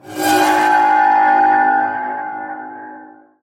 Horror Sfx